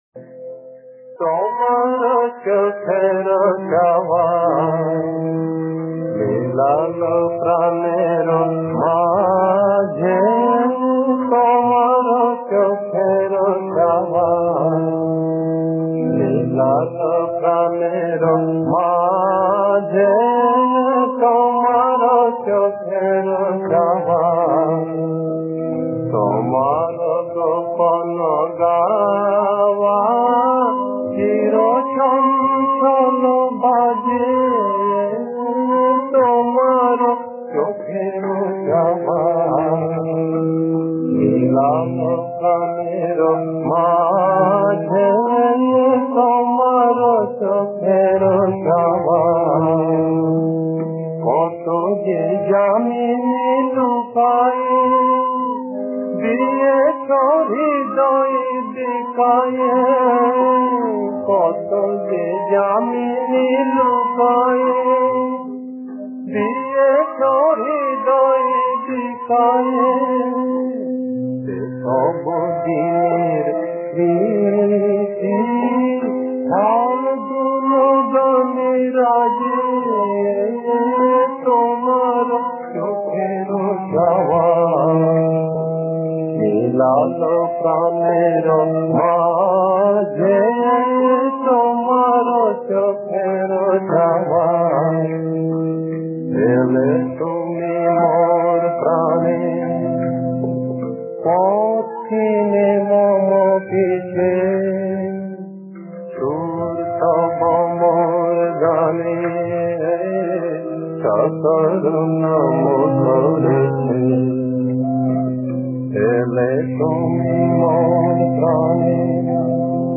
Bengali Film Song